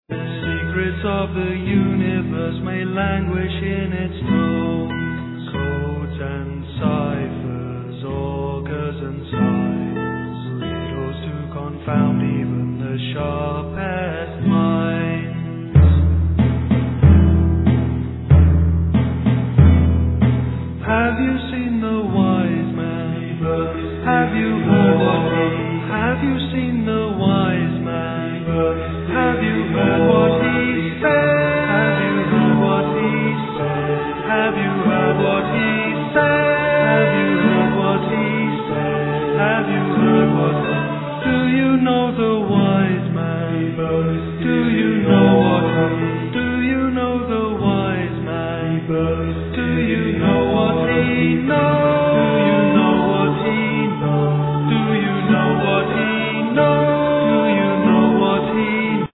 Recorder
Oboe d'amore
Piano, Guitar
Voice, Violin